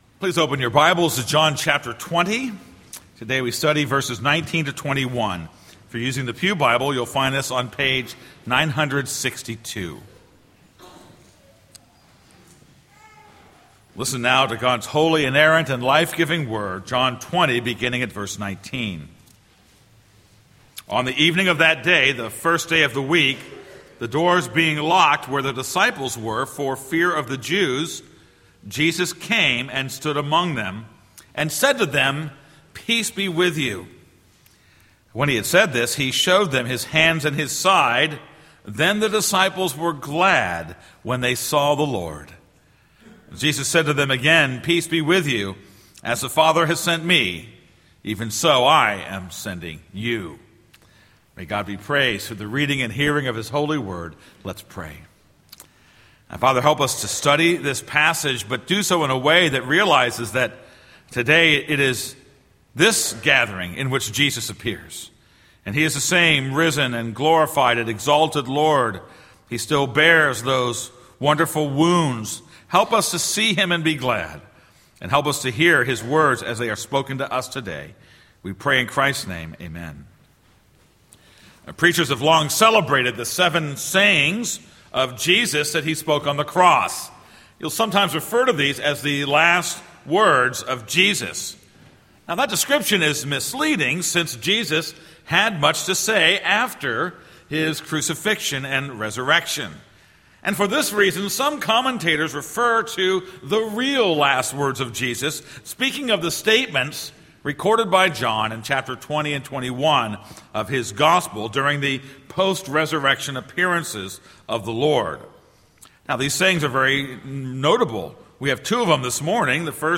This is a sermon on John 20:19-21.